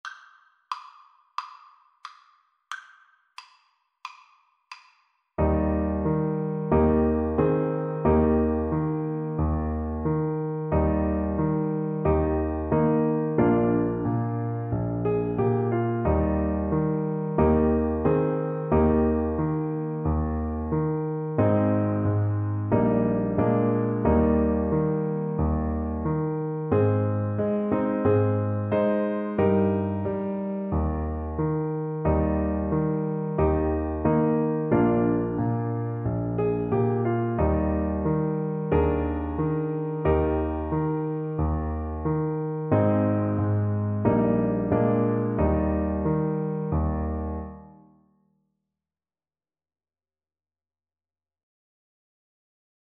Traditional James Scott Skinner The Cradle Song Trombone version
Trombone
Eb major (Sounding Pitch) (View more Eb major Music for Trombone )
Andante =c.90
4/4 (View more 4/4 Music)
Traditional (View more Traditional Trombone Music)
Lullabies for Trombone